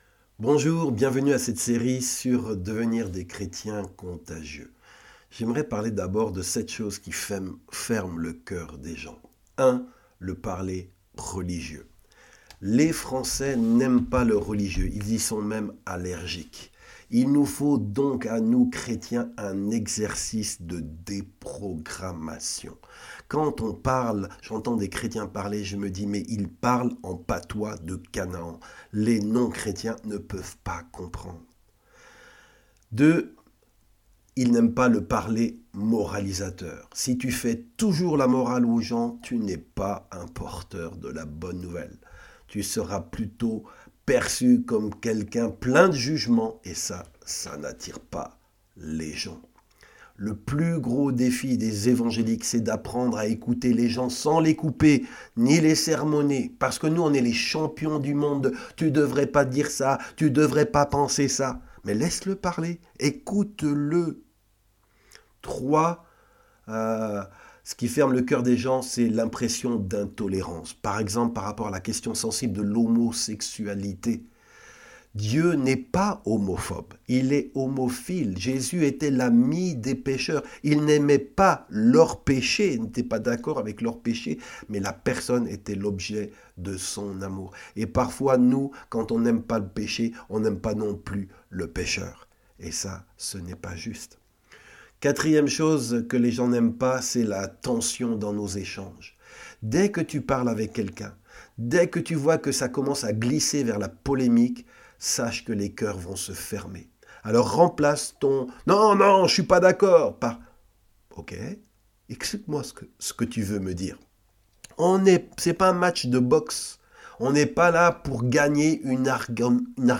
Des messages audio chrétiens